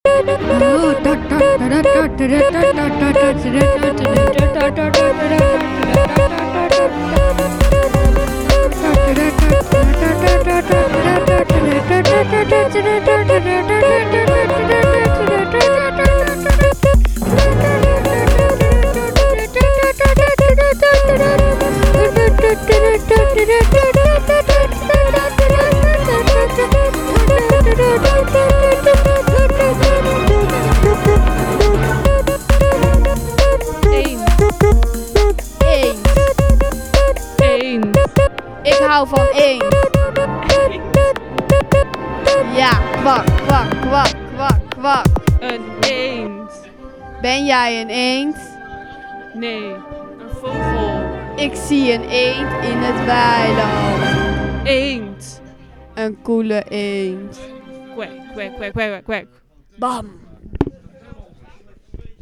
5 juli ’24 – Zomerfeest Tinte